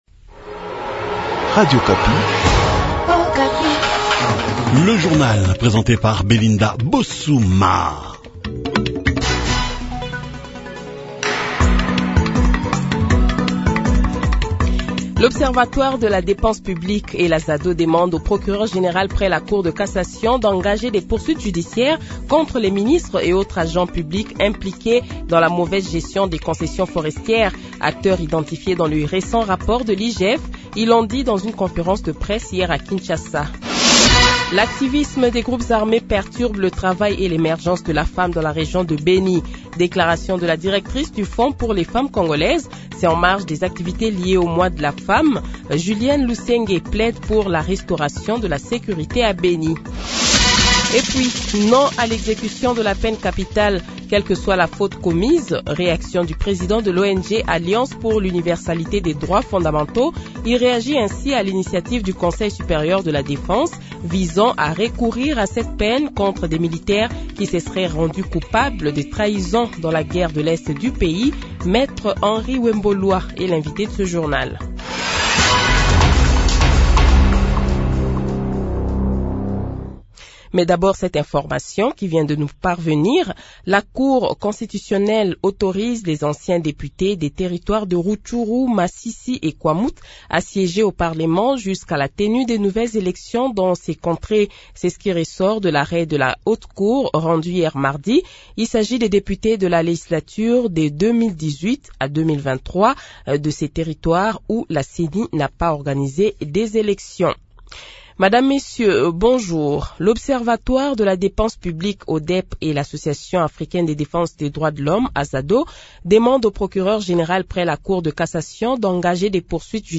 Journal Francais Matin
Le Journal de 7h, 06 Mars 2024 :